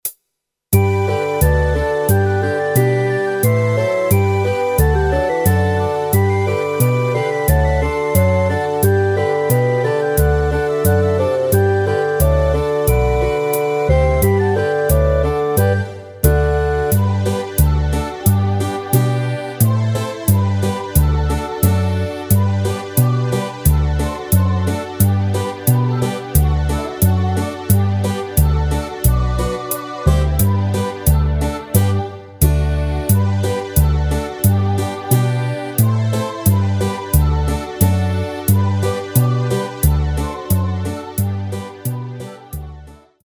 Rubrika: Vánoční písně, koledy